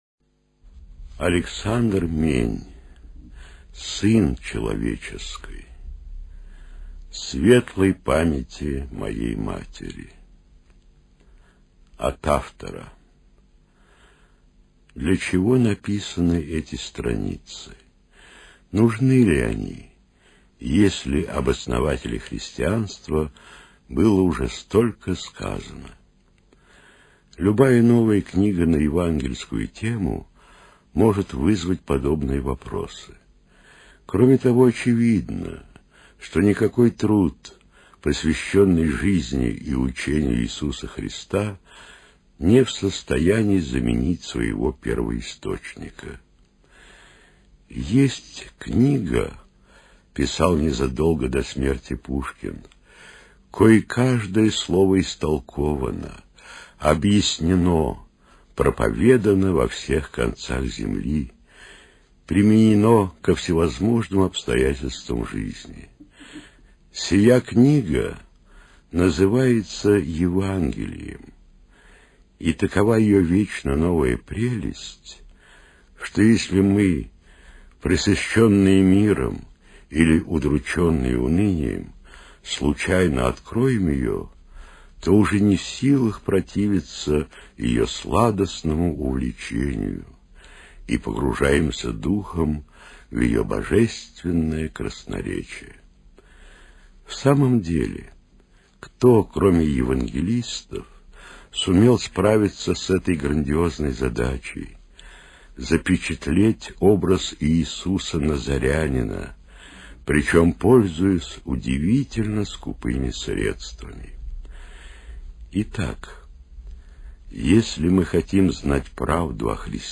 Студия звукозаписиРостовская областная библиотека для слепых